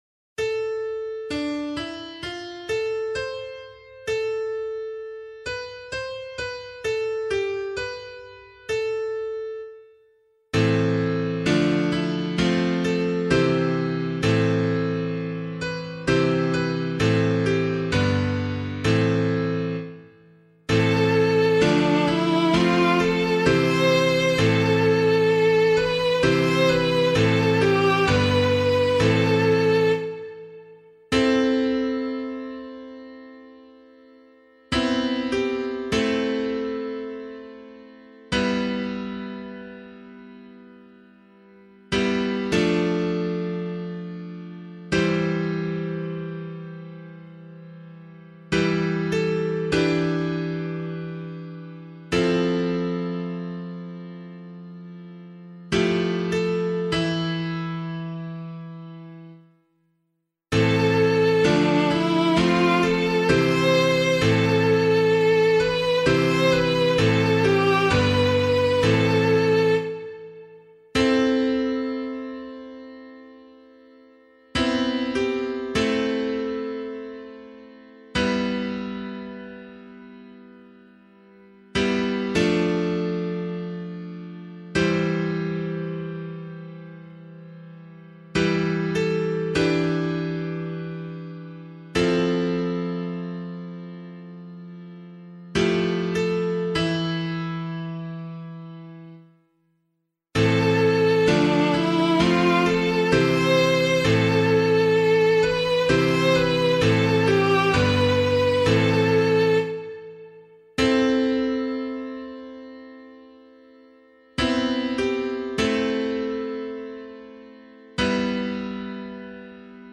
002 Advent 2 Psalm B [Abbey - LiturgyShare + Meinrad 4] - piano.mp3